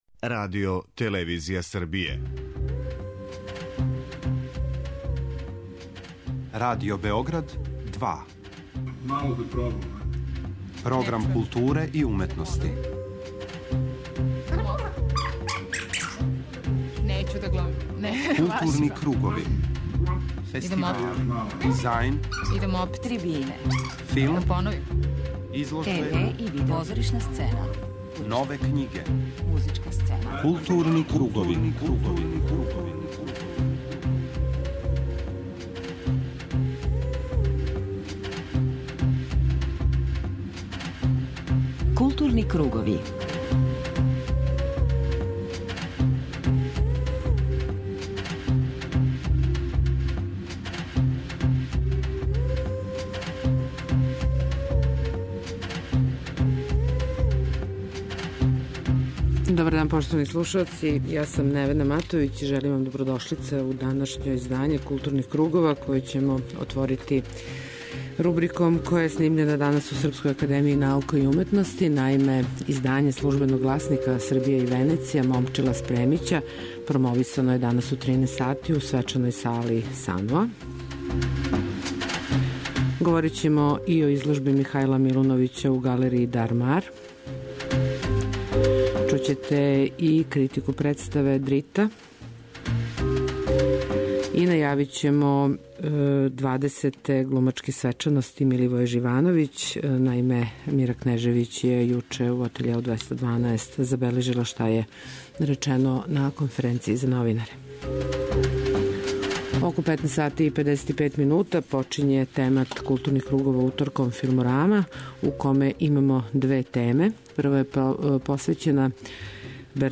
У интервјуу који је снимљен на 8. Међународном филмском и музичком фестивалу Кустендорф , чућете какав однос према такмичењима, наградама и колегама има овај редитељ и филмски критичар, али и како говори о истини за којом трага у свим својим филмовима.